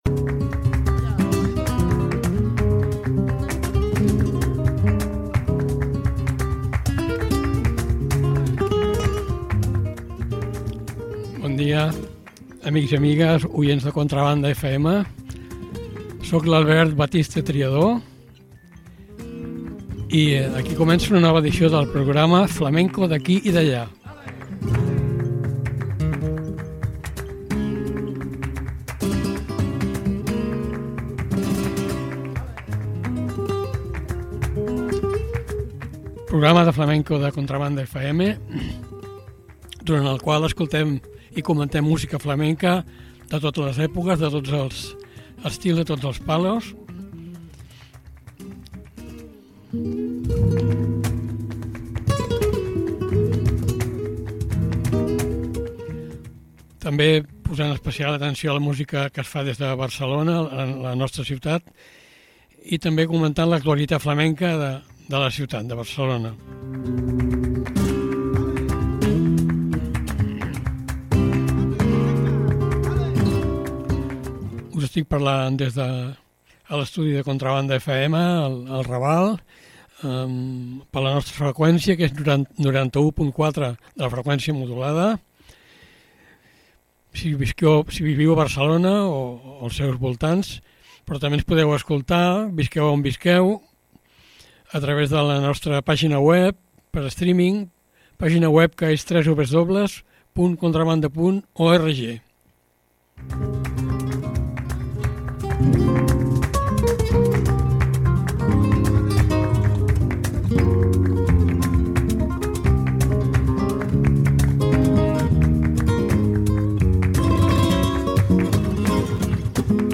bulerías.
sevillanas.
soleá.
rondeña.
tangos.